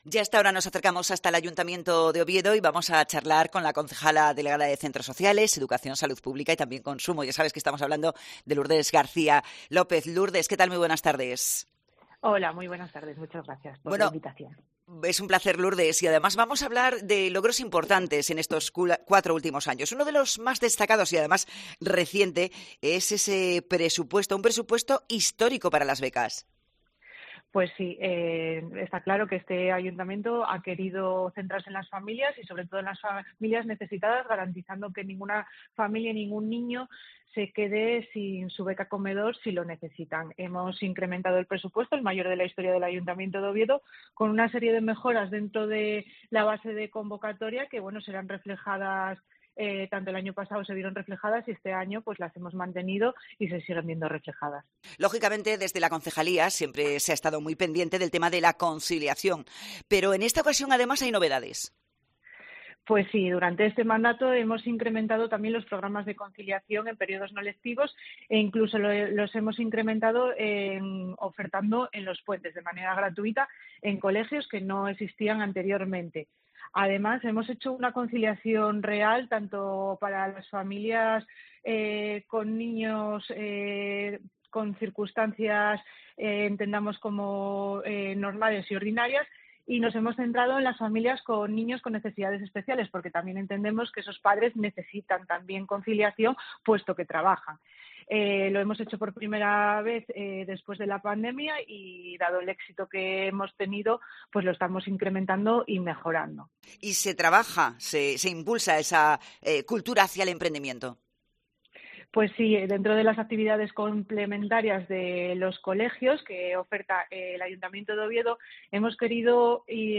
Entrevista a Lourdes García, concejala de Educación, Centros Sociales, Salud Pública y Consumo de Oviedo